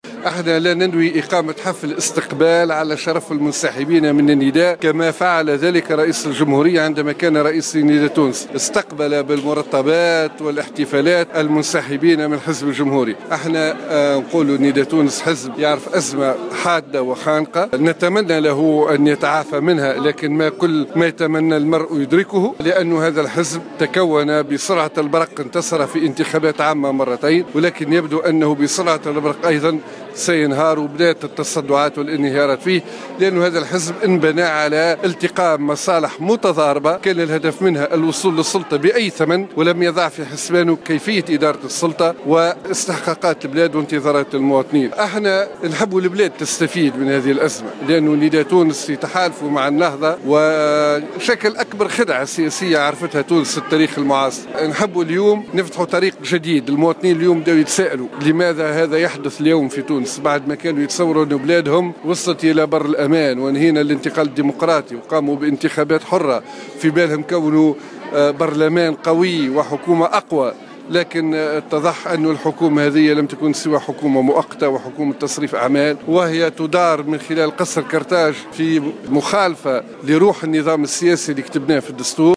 رجّح القيادي بالحزب الجمهوري عصام الشابي، انهيار حزب نداء تونس بسرعة البرق بحسب تعبيره في تصريحات صحفية اليوم السبت.